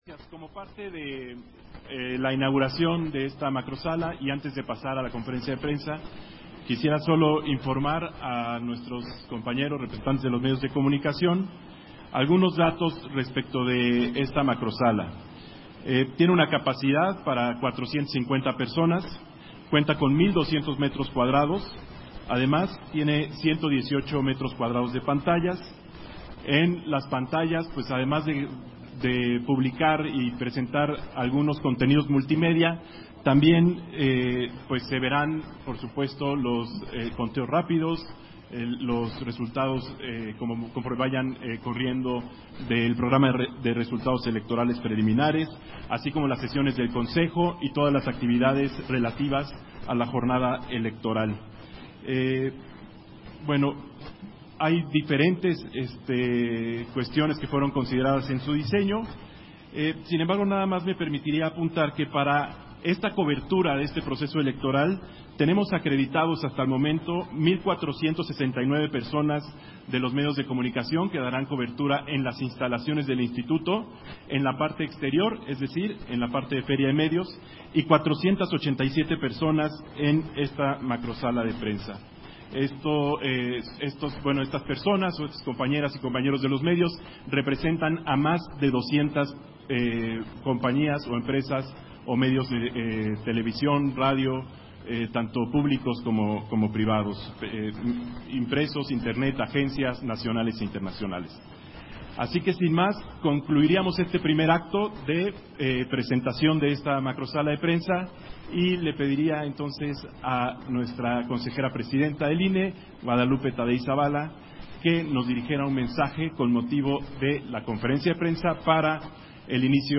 300524_AUDIO_CONFERENCIA-DE-PRENSA - Central Electoral